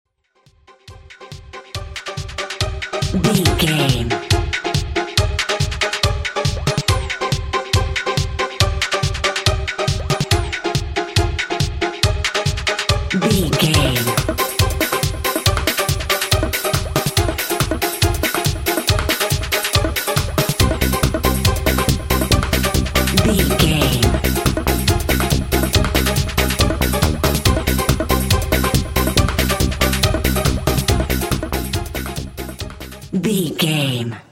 Aeolian/Minor
Fast
funky
groovy
uplifting
futuristic
energetic
drums
synthesiser
drum machine
house
techno
electro house
dance music